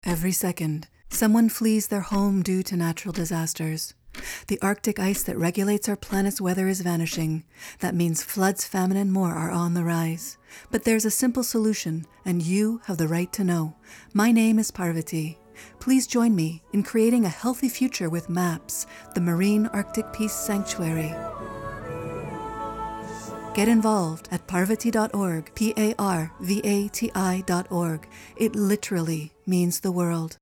Type: PSA
320kbps Stereo